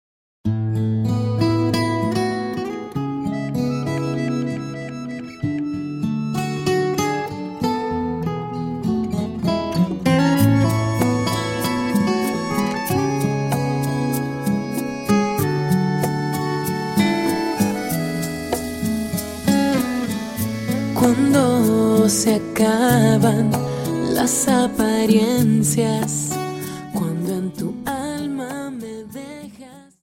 Dance: Rumba 24